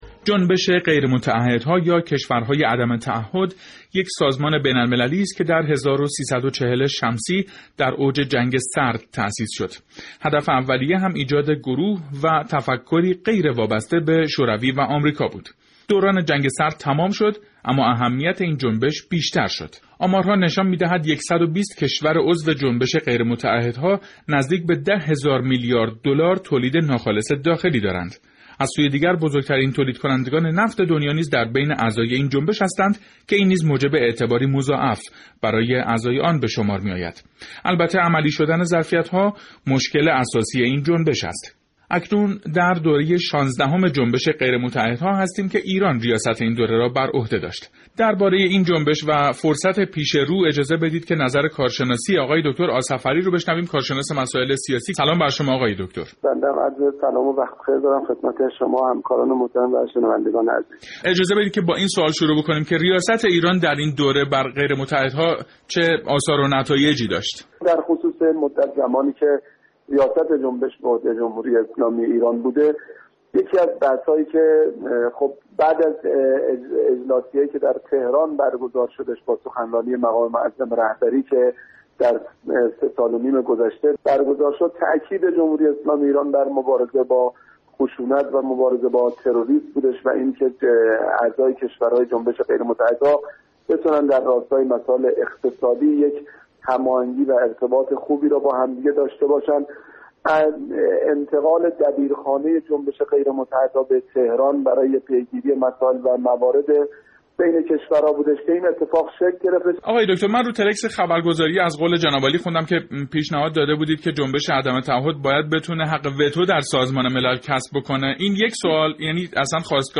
дар гузорише ба баррасии амалкарди ин ҷунбиш пардохтааст, ки мешунавем: